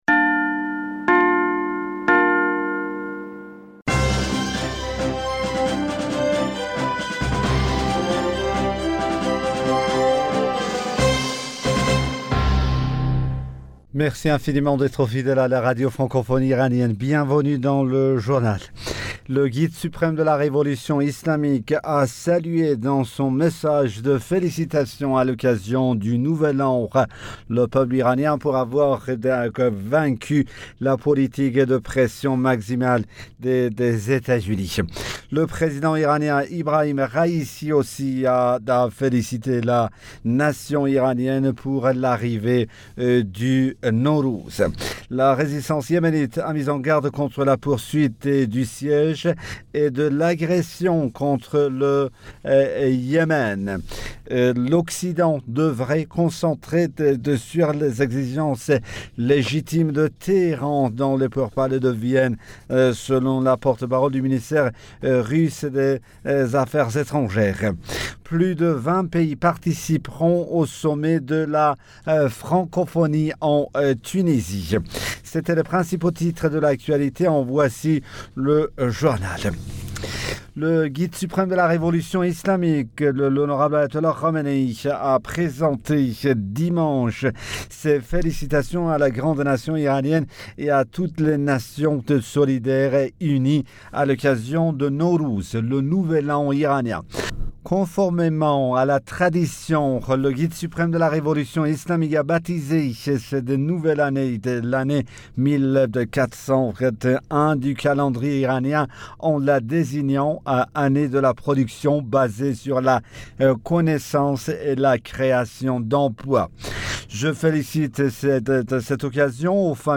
Bulletin d'information Du 21 Mars 2022